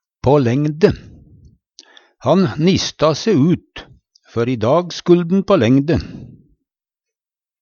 på lengde - Numedalsmål (en-US)